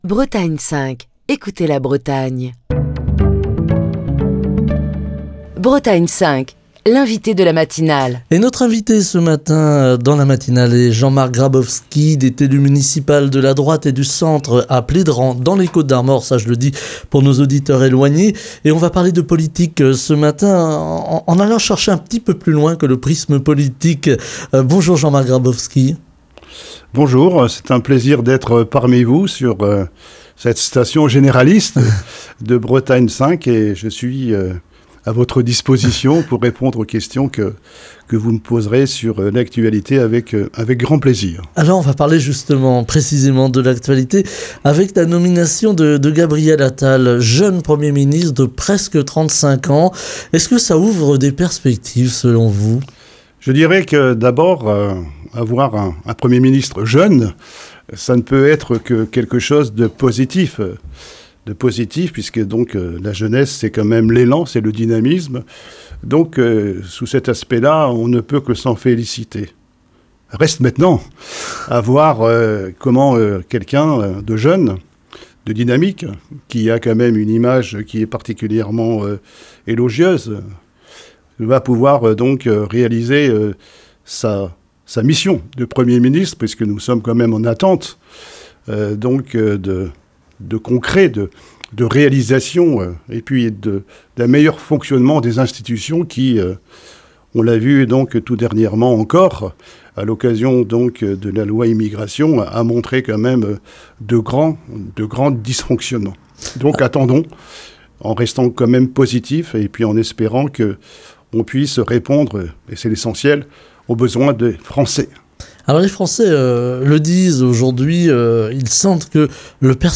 Ce mercredi dans Bretagne 5 Matin, il est question de la nomination de Gabriel Attal qui devient le plus jeune Premier ministre français. Avec notre invité Jean-Marc Grabowski, élu municipal de la Droite et du Centre à Plédran (22), nous revenons également sur le fonctionnement des institutions, la décentralisation, la régionalisation et l'autonomie et enfin sur le ras-le-bol des maires et plus largement des élus qui jettent l'éponge face aux difficultés administratives et aux incivilités.